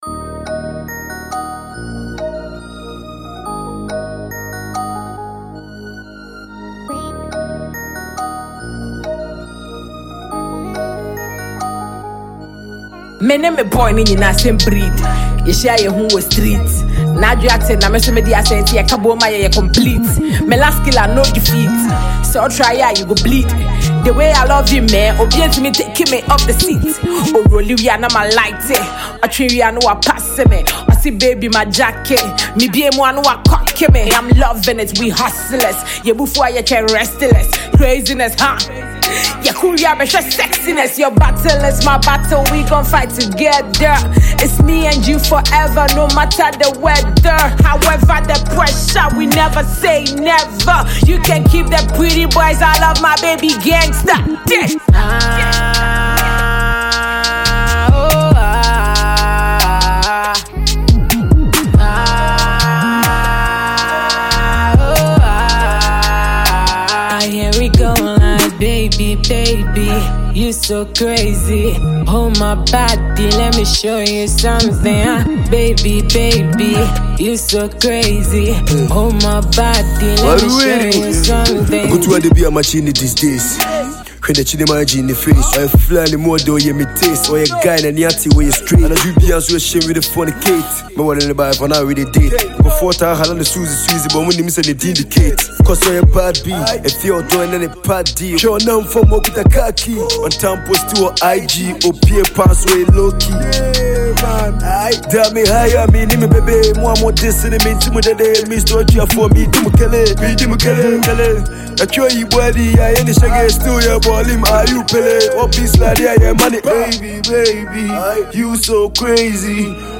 Ghana Music
a leading figure in Ghanaian hip-hop
blending modern beats with traditional Ghanaian influences.
The infectious rhythm and engaging melodies